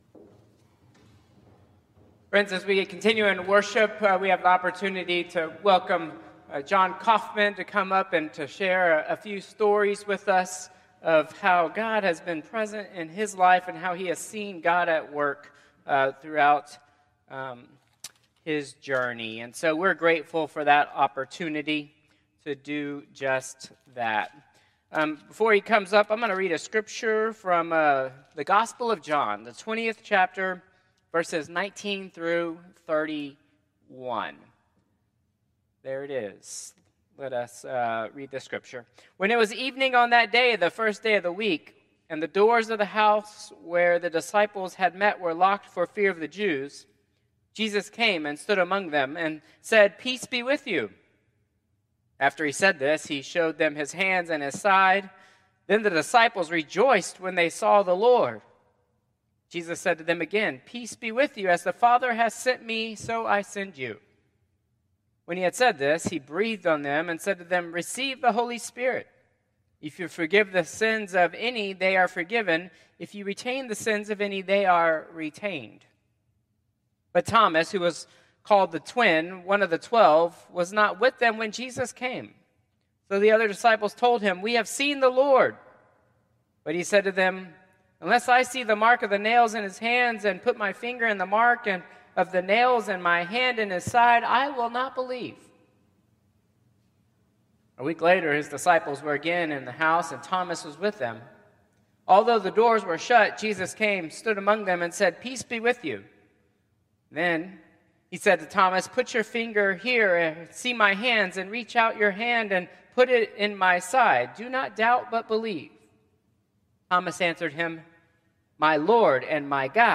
Traditional Service 4/27/2025